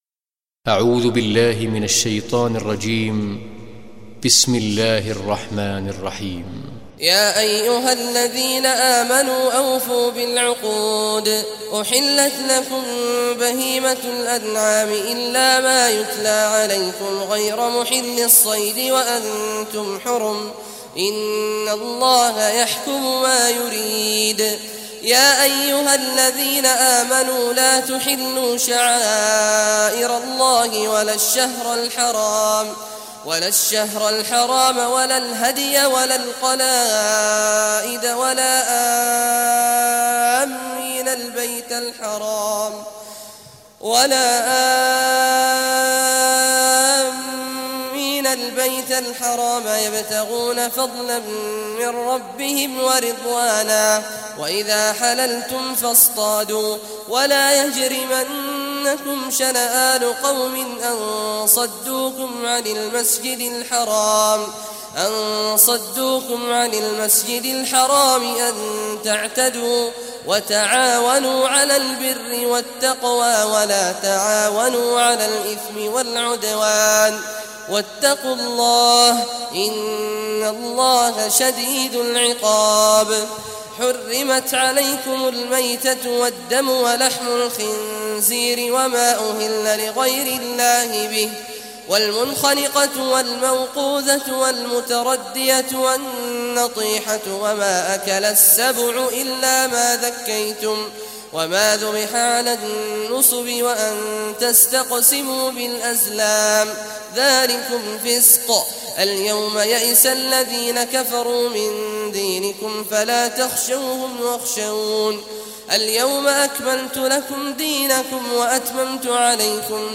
Surah Maida Recitation Sheikh Abdullah Al Juhany
Surah Maidah, listen or play online mp3 tilawat / recitation in Arabic in the beautiful voice of Sheikh Abdullah Awad al Juhany.